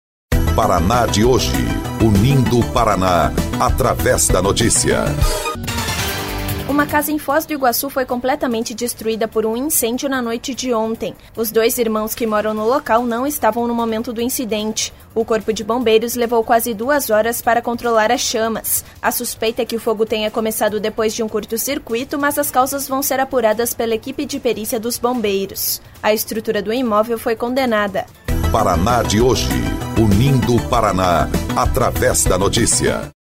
BOLETIM - Incêndio destrói casa em Foz do Iguaçu